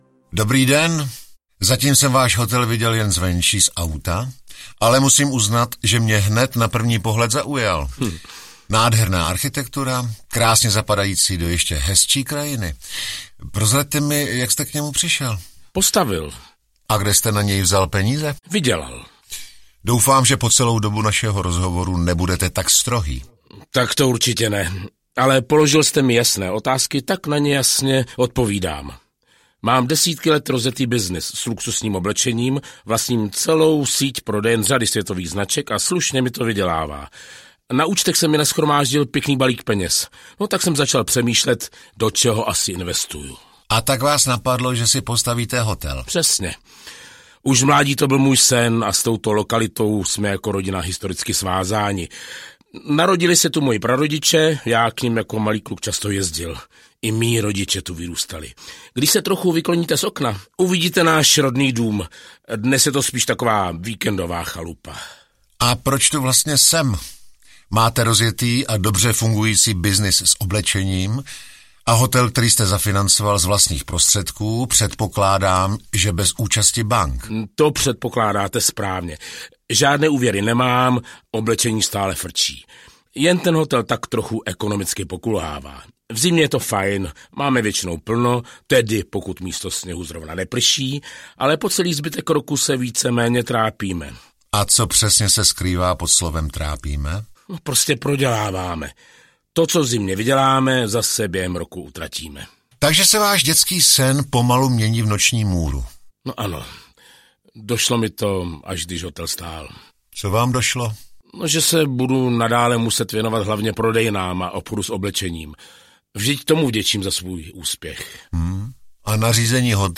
Krotitel rizik podnikani zasahuje: Hotel audiokniha
Ukázka z knihy